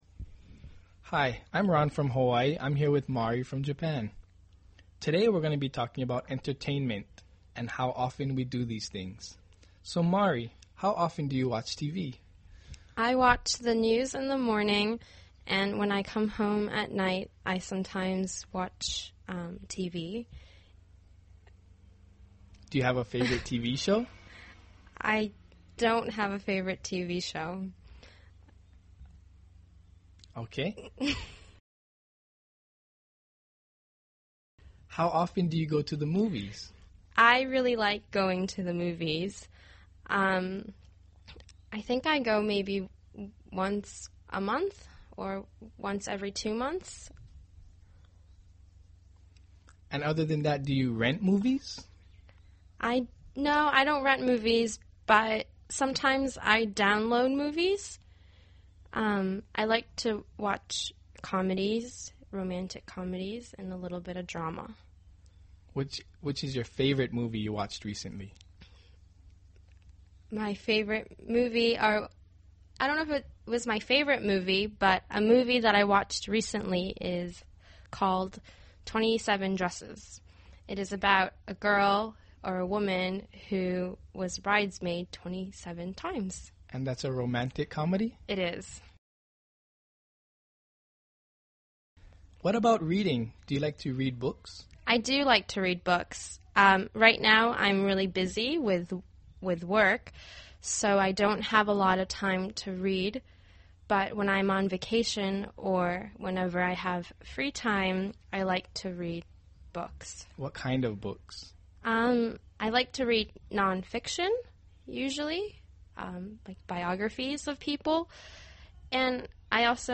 英语初级口语对话正常语速09：玛丽的媒体选择（mp3+lrc）